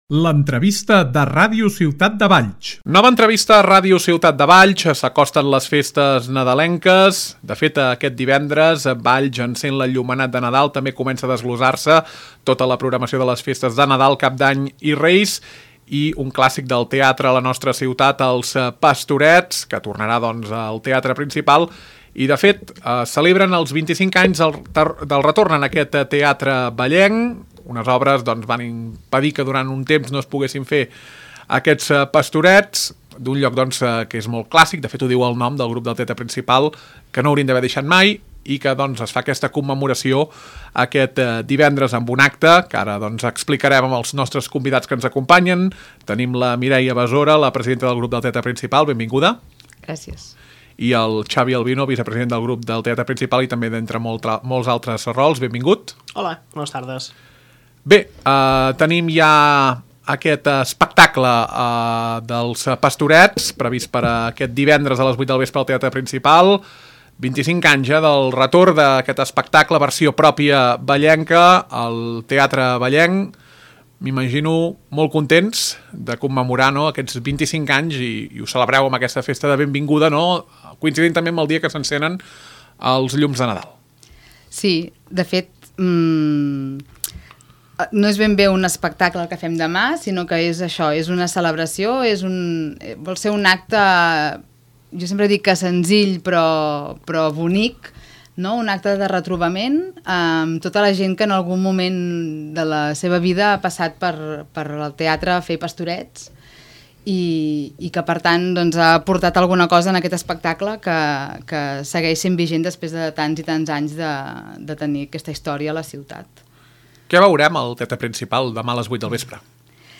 Nova entrevista a Ràdio Ciutat de Valls. Abordem l’acte d’aquest divendres del 25è aniversari del retorn dels Pastorets al Teatre Principal i les novetats de les representacions d’enguany que començaran el proper 26 de desembre.